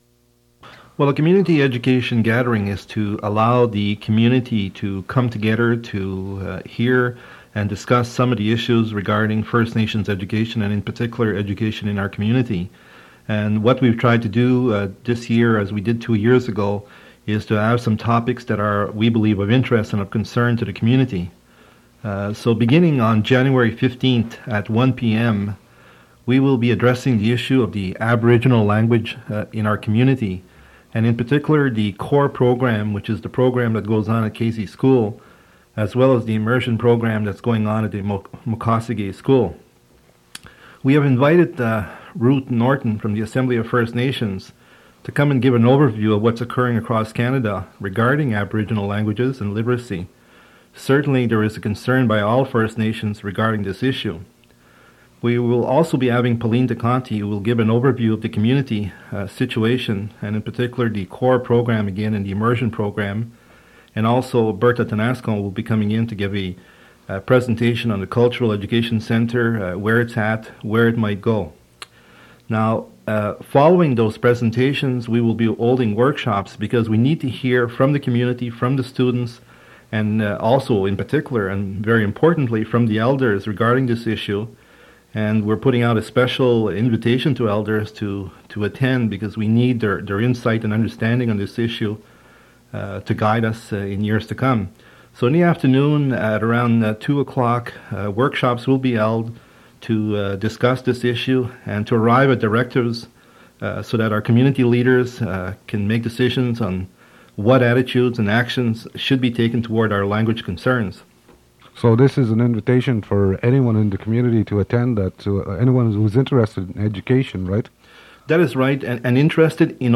Fait partie de The community meeting on education